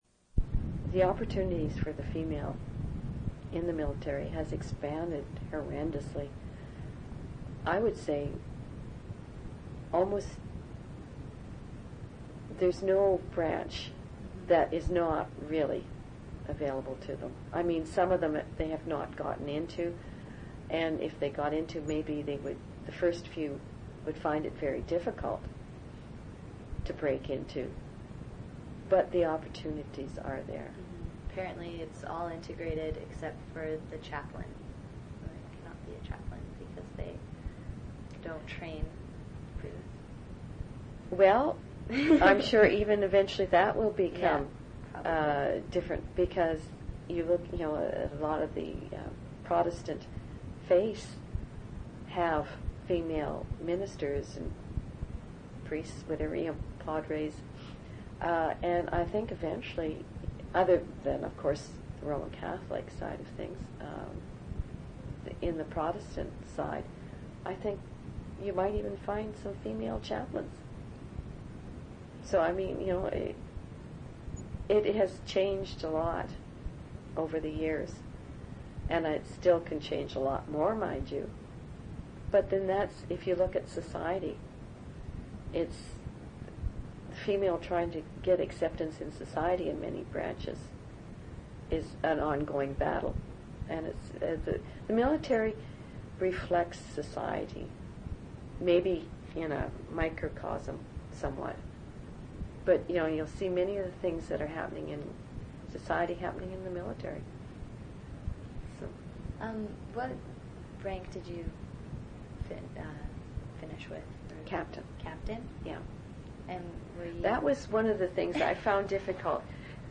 Original sound recording on audio cassette also available.
oral histories (literary genre) reminiscences